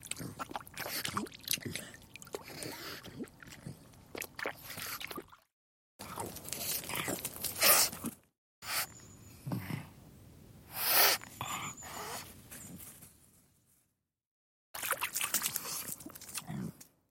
Здесь вы можете скачать или послушать онлайн странные шумы, шаги и другие аудиофрагменты.
Звук домового пьющего воду